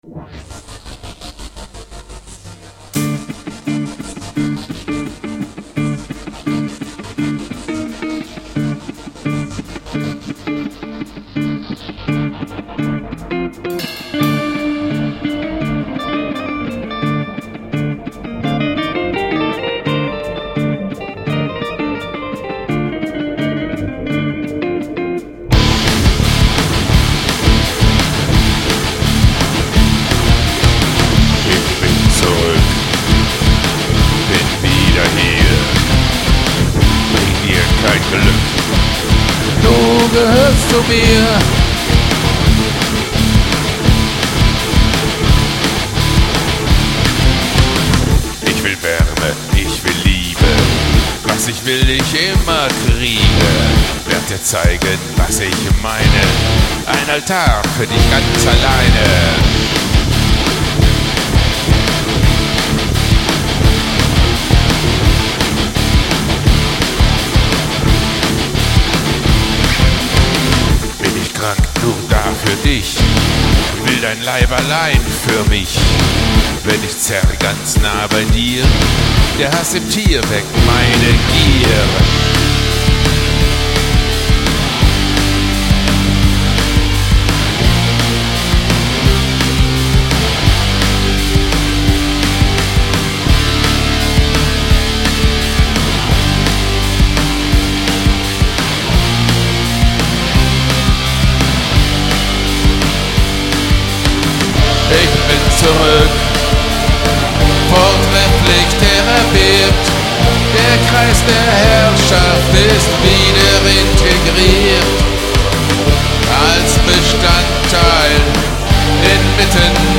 NDH-Band